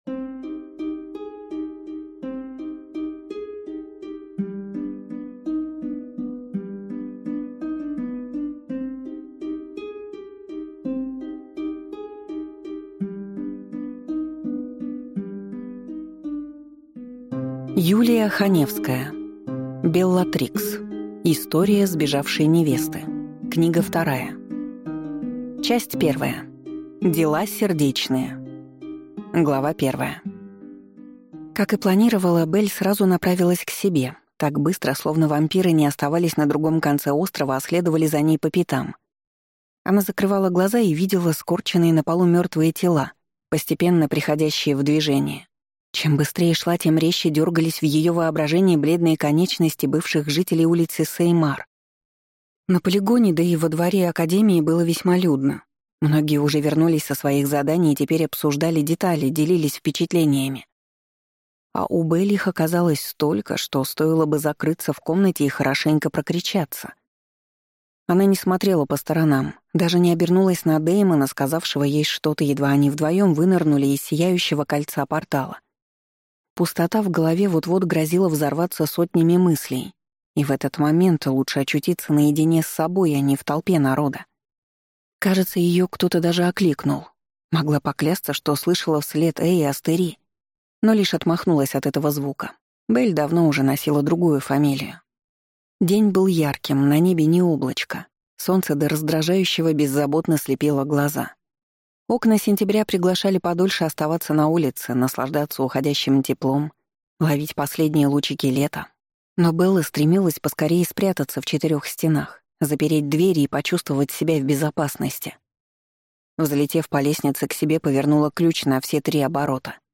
Аудиокнига Беллатрикс. История сбежавшей невесты. Книга 2 | Библиотека аудиокниг